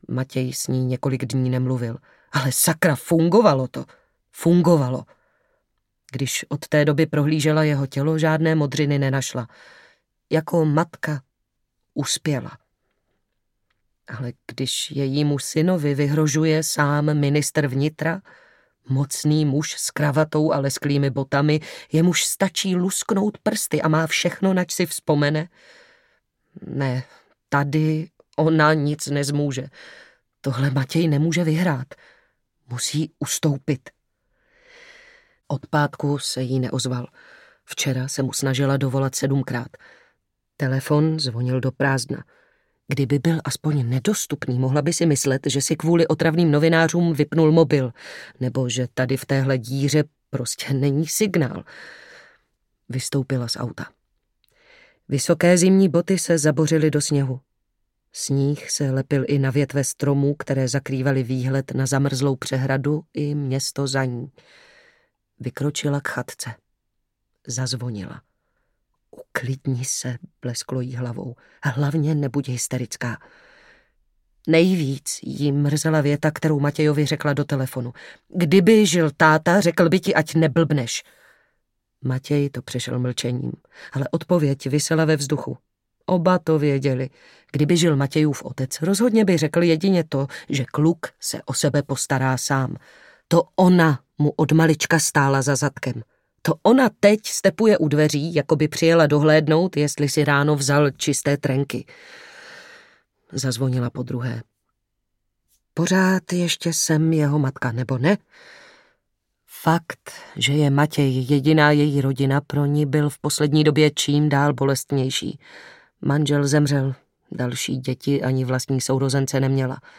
Šelma audiokniha
Ukázka z knihy
Vyrobilo studio Soundguru.